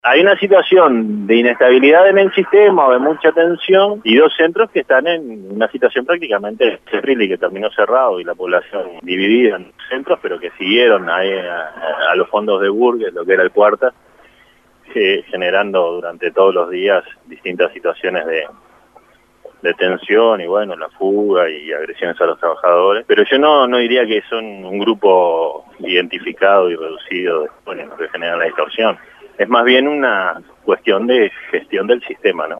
Entrevistada por Rompkbzas, Fulco dijo que se debe "recimentar"; el sistema y para ello es necesario cambiar la forma de seleccionar a los funcionarios que trabajan en los centros de privación de libertad y crear una escuela destinada a la formación de estos educadores, una tarea que requiere "una alta especialización";.